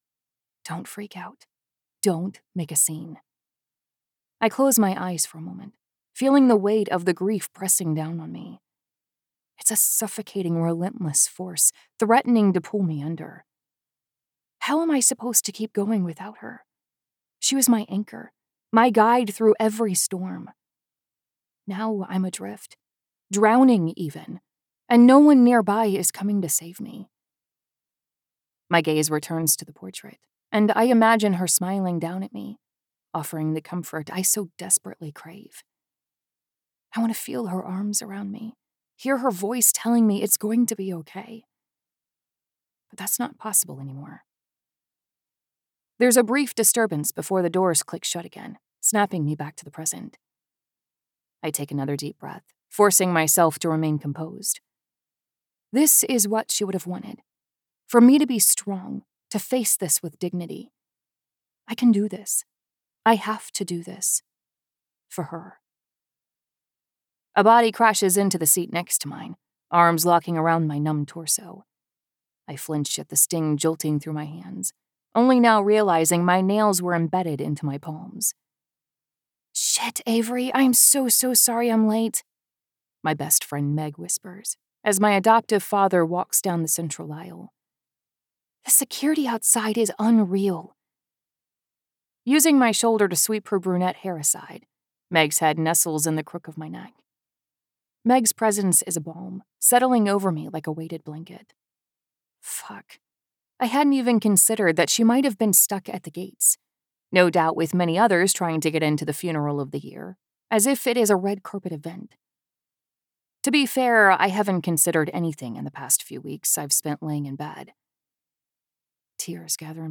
Narrator
Accent Capabilities: General American, Southern American.